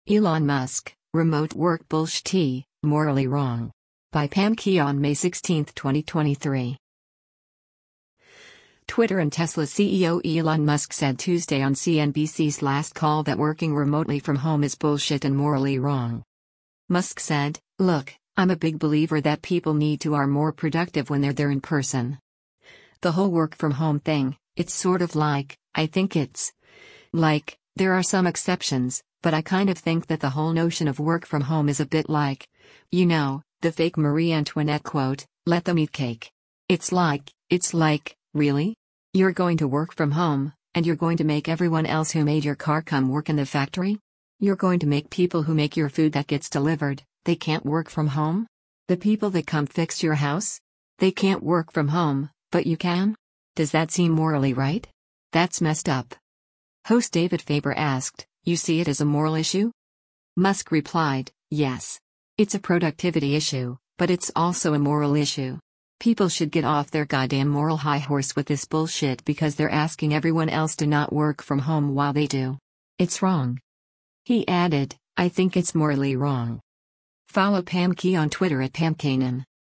Twitter and Tesla CEO Elon Musk said Tuesday on CNBC’s “Last Call” that working remotely from home is “bullshit” and “morally wrong.”